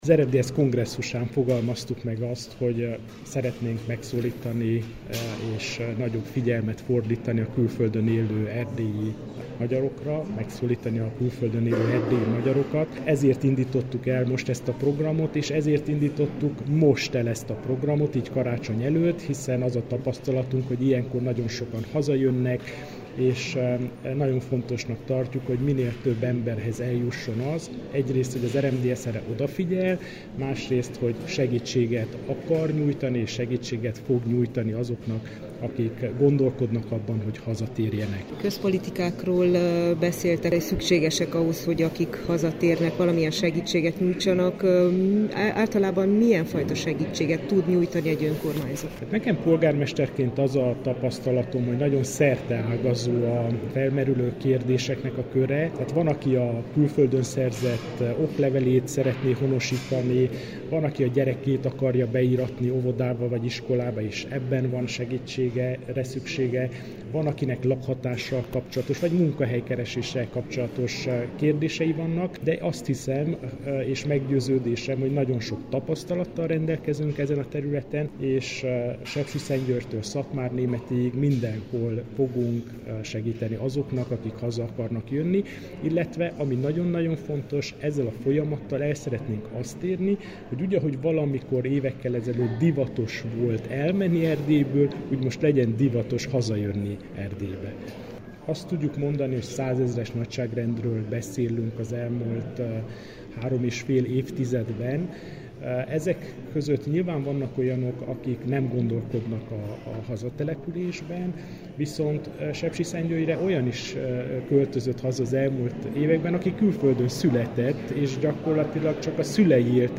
Antal Árpád polgármester hallják.